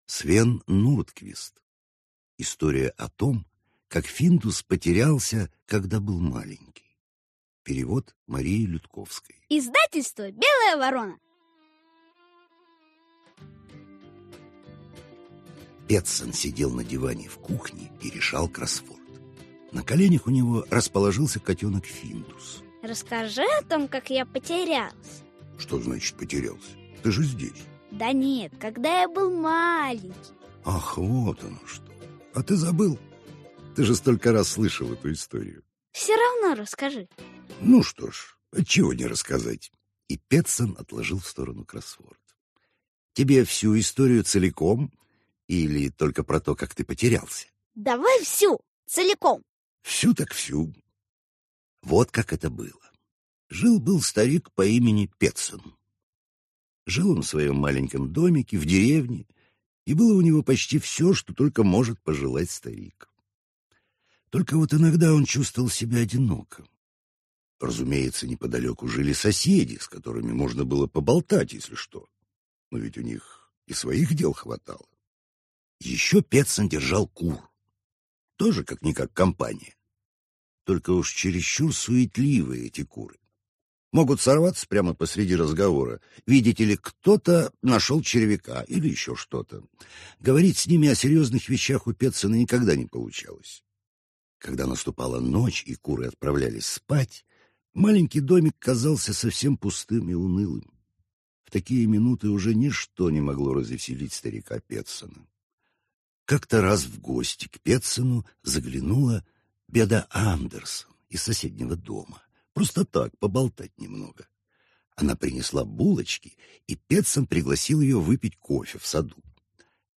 Аудиокнига История о том как Финдус потерялся, когда был маленький | Библиотека аудиокниг